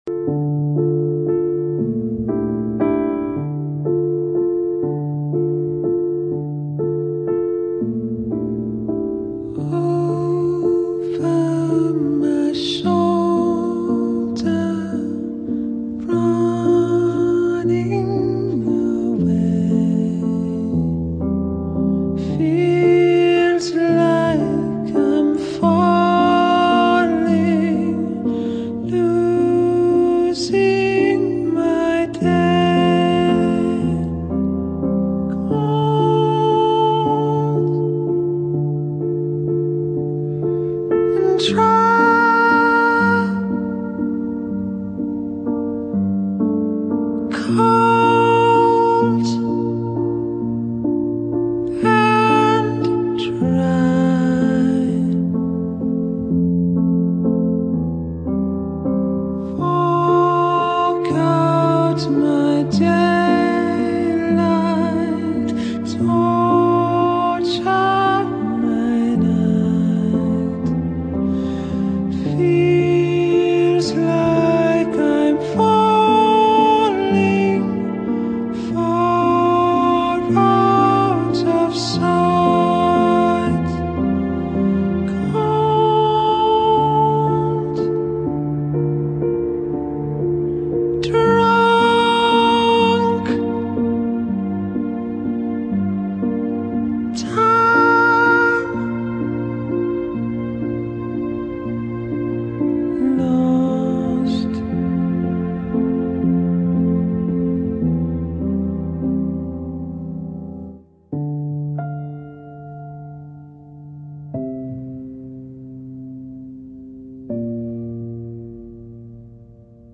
It’s sad and beautiful and weirdly filled with hope.